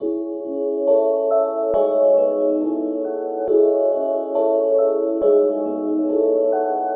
Witcha_Bell .wav